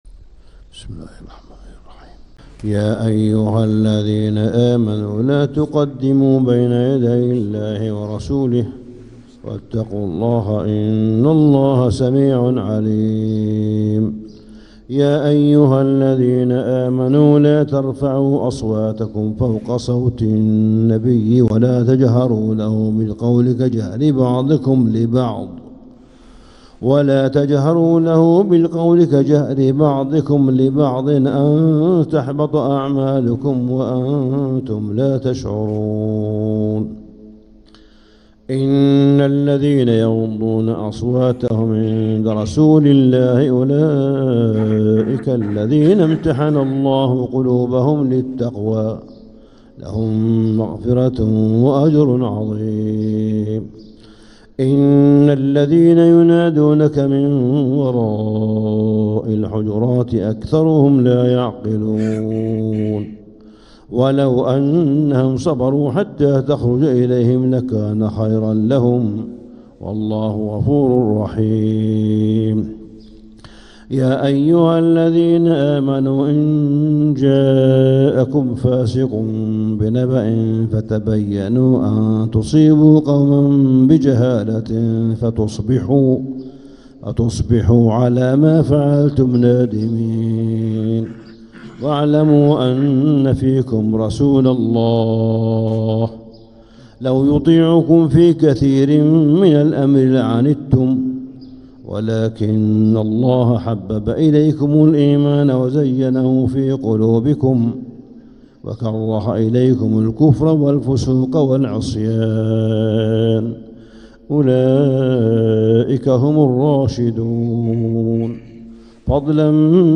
سورة الحجرات 9-8-1446هـ | Surah Al-Hujurat > السور المكتملة للشيخ صالح بن حميد من الحرم المكي 🕋 > السور المكتملة 🕋 > المزيد - تلاوات الحرمين